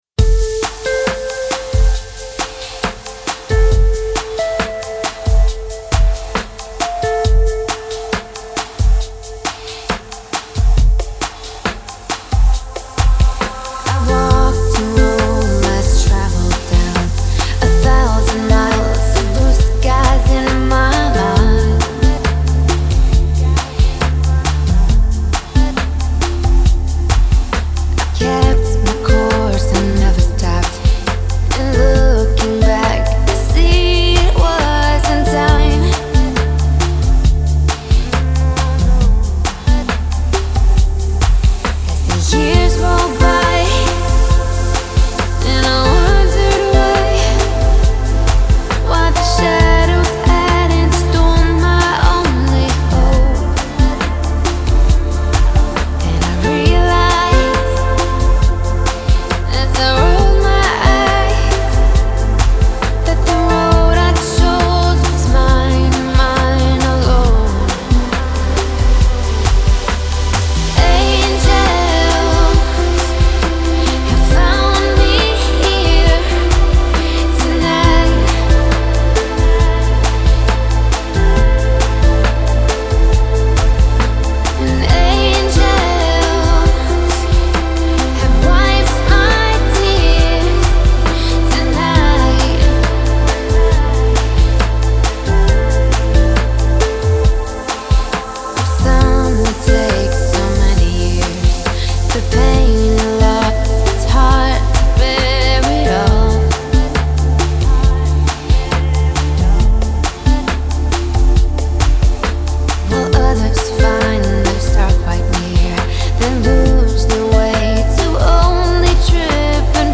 音乐流派: Chillout, new age